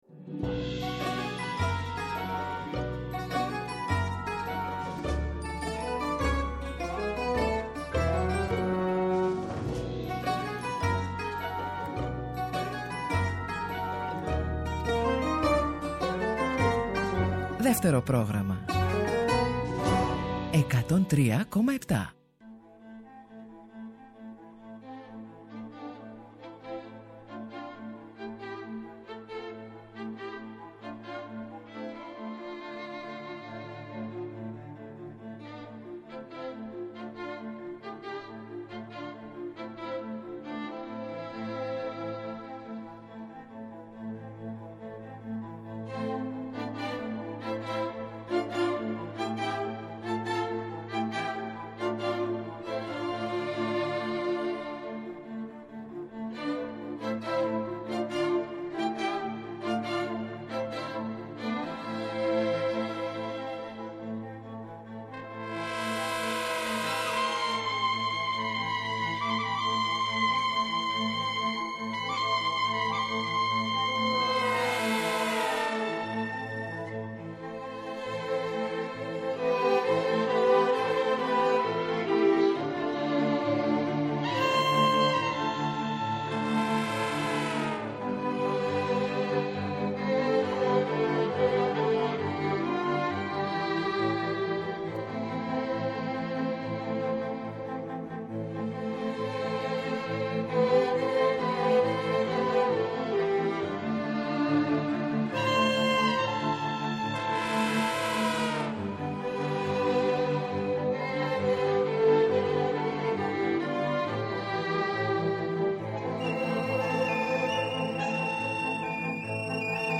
μετέδωσε στη σημερινή εκπομπή του τραγούδια που ακούστηκαν σε μεταφορές κλασικών βιβλίων της ελληνικής λογοτεχνίας στην τηλεόραση, τον κινηματογράφο και το θέατρο.